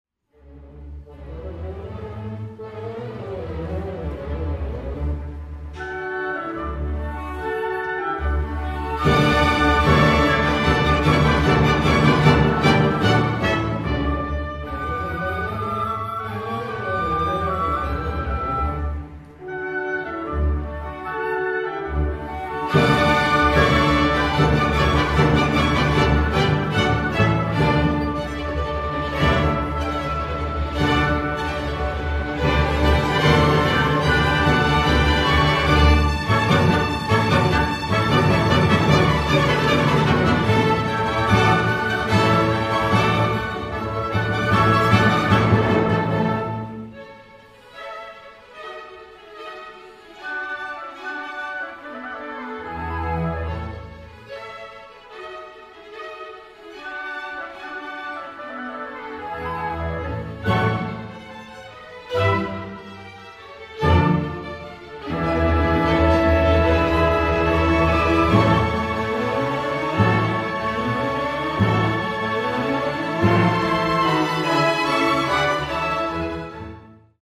Se trata de la Obertura “Las Bodas de Fígaro” del genial compositor de Salzburgo, Mozart; Extractos de las Suites No.1 y  No. 2 de Peer Gynt del autor noruego Grieg y la Sinfonía No.8 “Inconclusa” del austriaco Schubert, todas obras de repertorio para gran orquesta como la OSY.
1-Obertura-las-bodas-de-Figaro.mp3